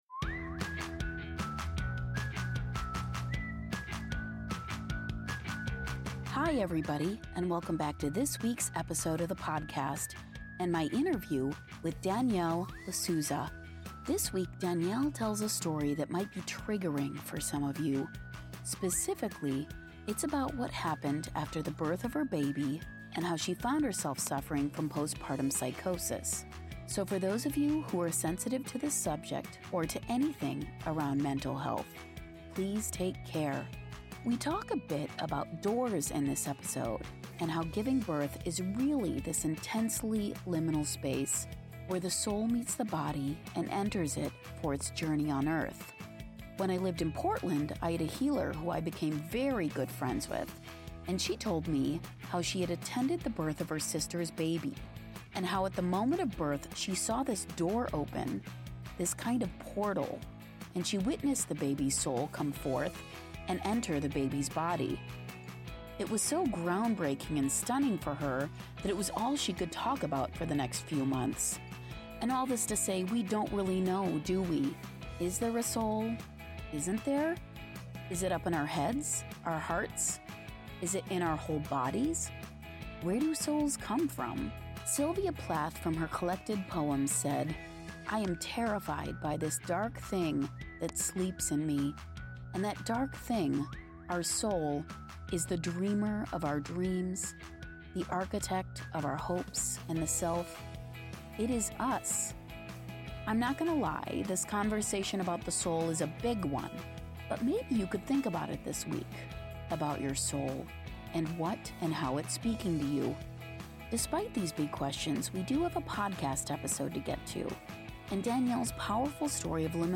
Byte Sized Blessings / The Interview